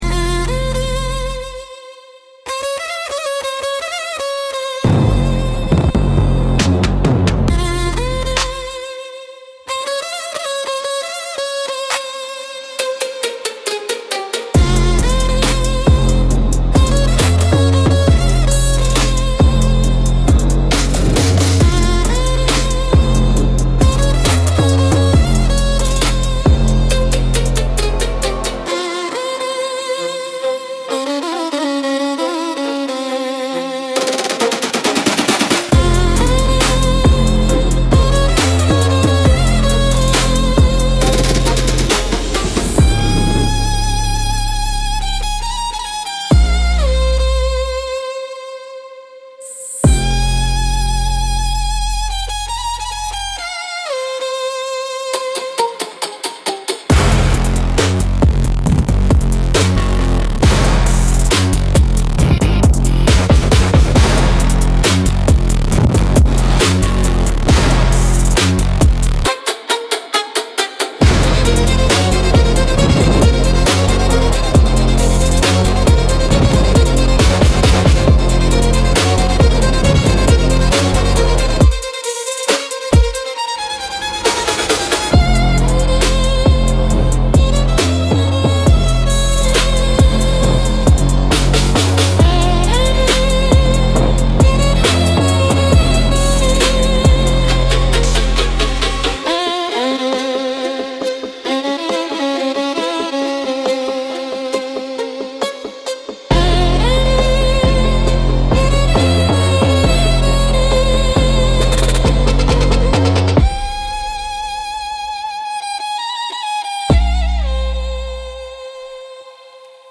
Instrumental - Sweet Provocation